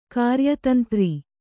♪ kāryatantri